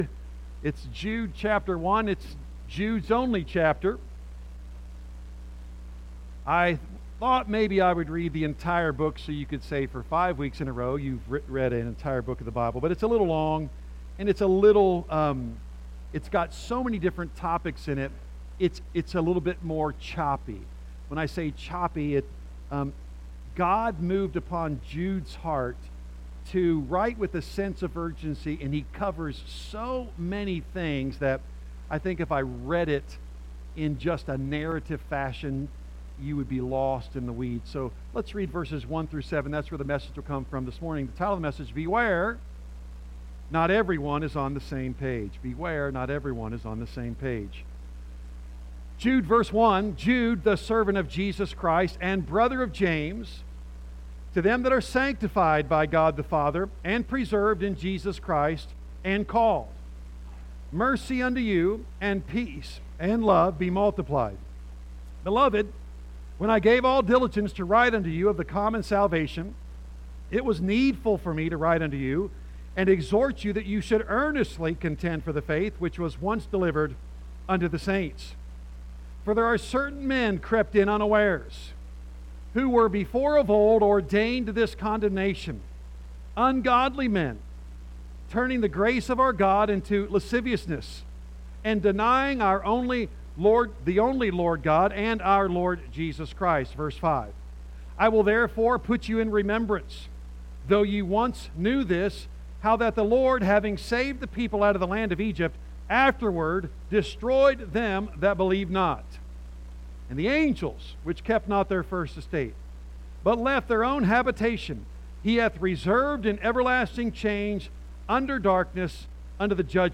A message from the series "Jude."